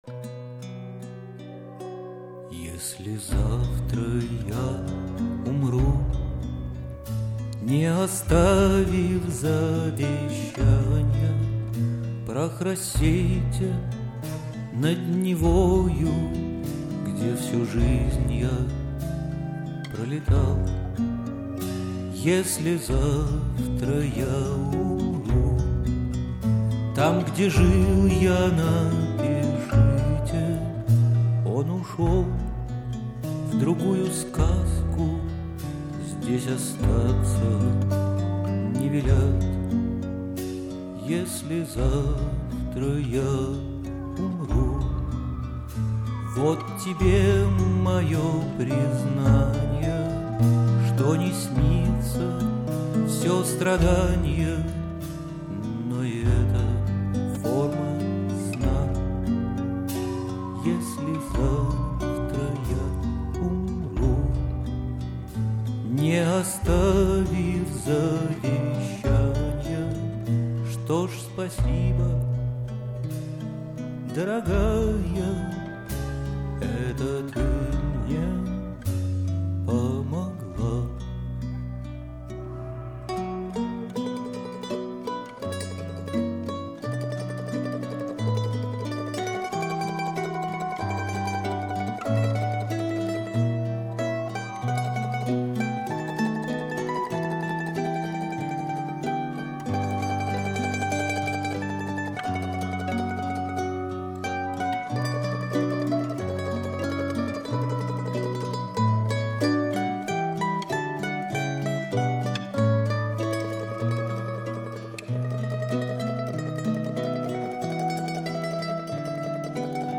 литавры, барабаны
Запись, сведение и мастеринг